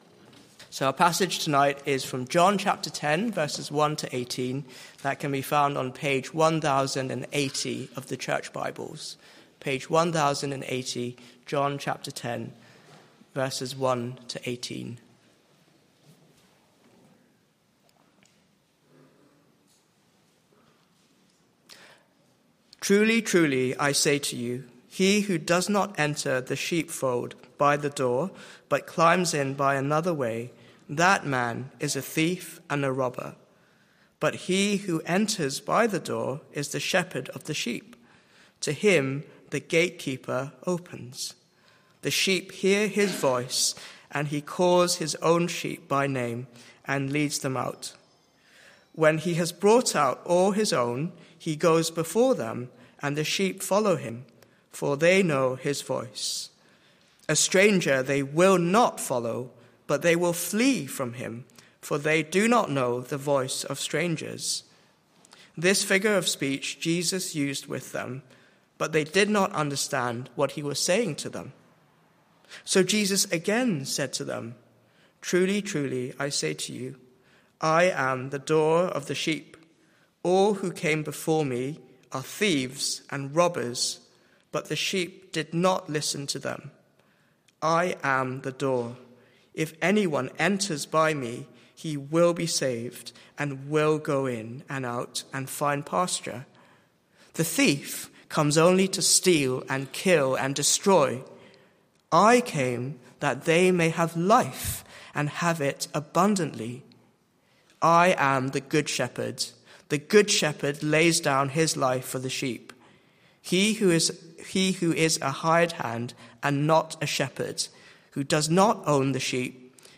Media for Evening Meeting on Sun 12th Jan 2025 18:00